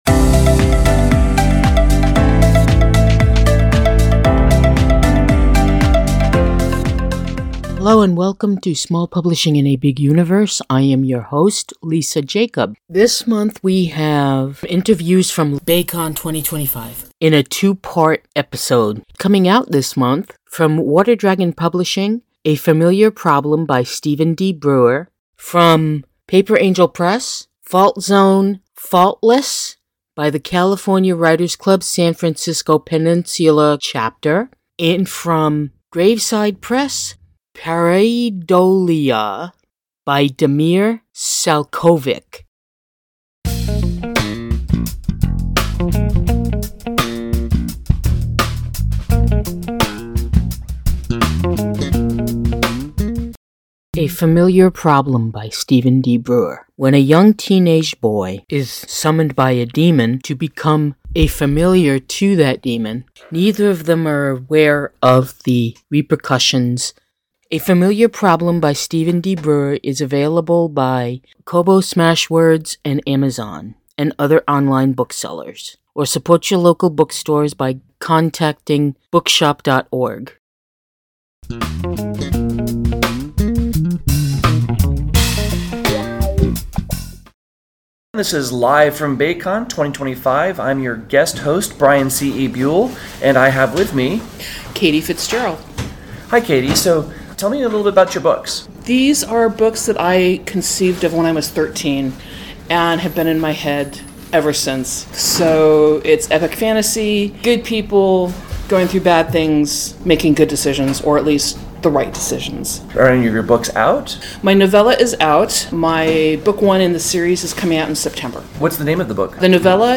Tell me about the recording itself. We are LIVE from Baycon 2025 / Westercon 77 from July 4, 2025 – July 7, 2025 in Santa Clara, California.